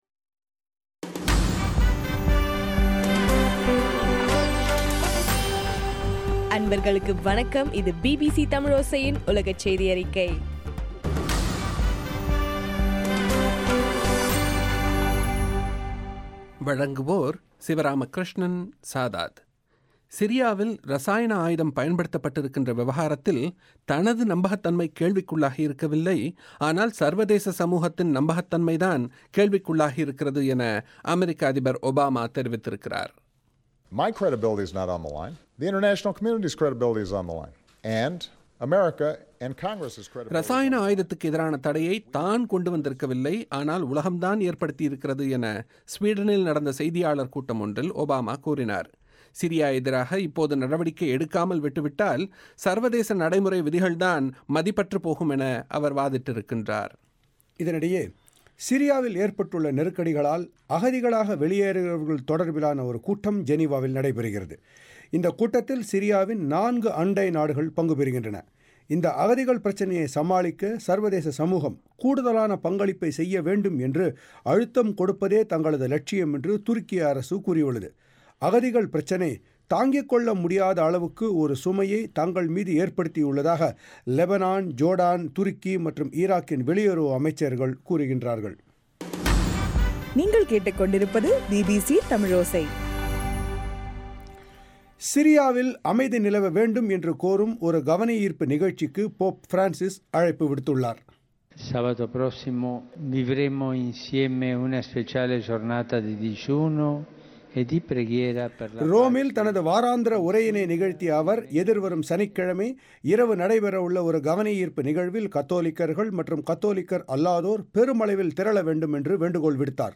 சக்தி எஃப் எம்மில் ஒலிபரப்பான பிபிசியின் உலகச் செய்தியறிக்கை.